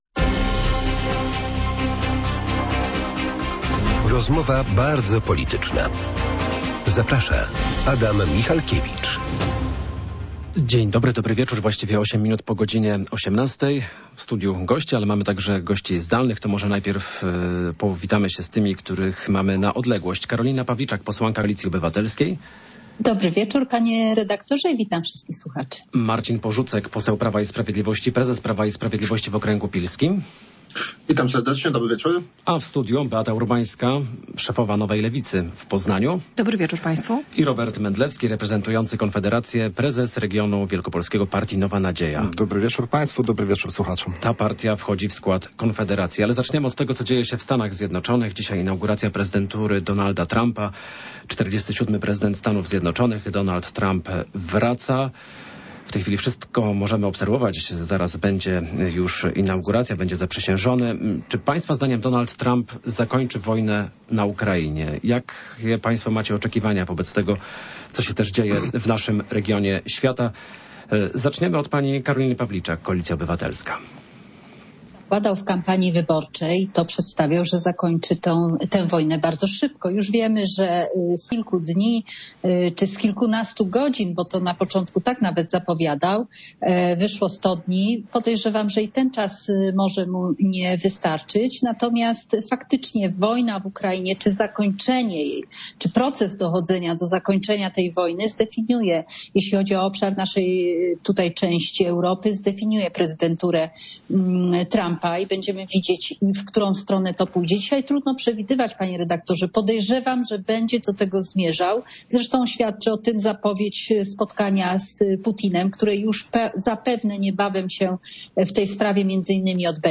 O nowym prezydencie USA i kampanii prezydenckiej w Polsce dyskutują goście Rozmowy bardzo politycznej w Radiu Poznań.